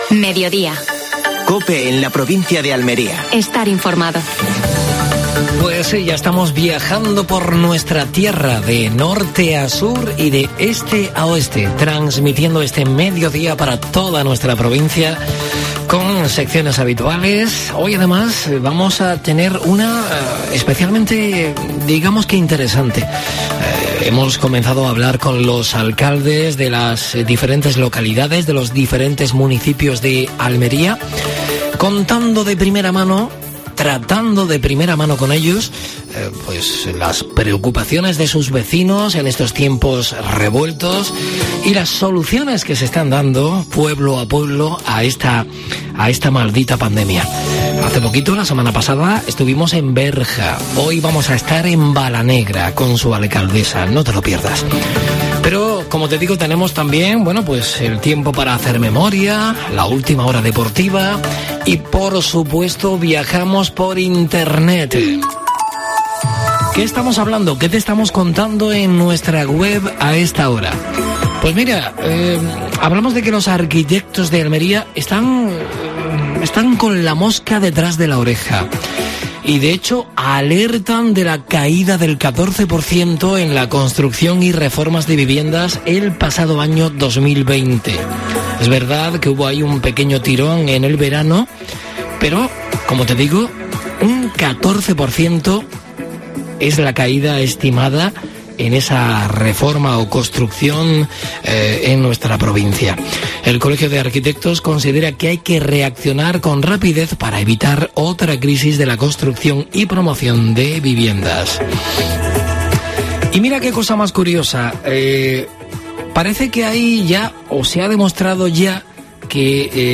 AUDIO: Actualidad en Almería. Entrevista a Nuria Rodríguez (alcaldesa de Balanegra). Última hora deportiva.